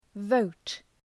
Προφορά
{vəʋt}